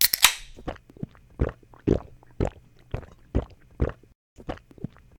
action_soda_0.ogg